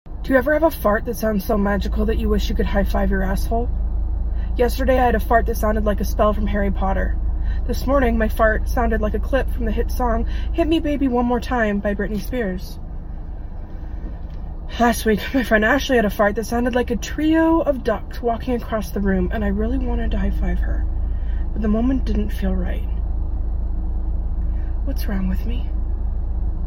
Tag your most magical fart sound effects free download